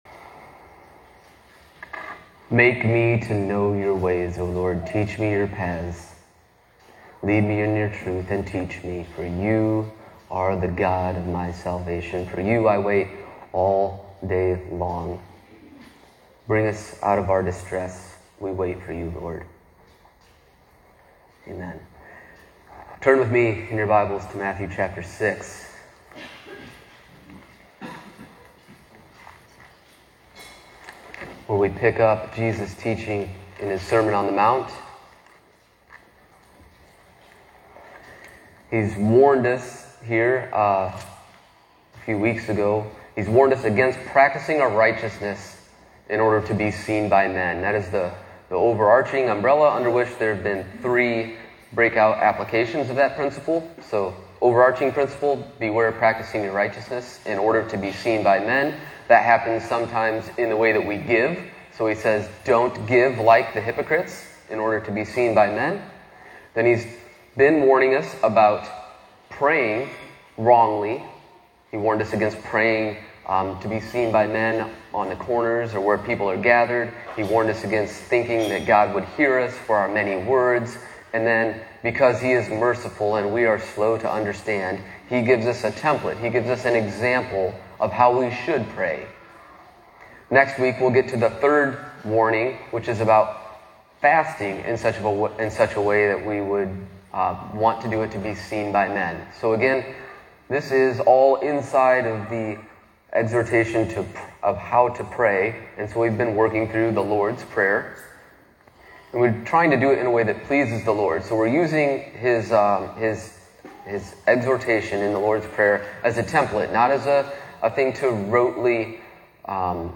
Covenant Reformed Fellowship Sermons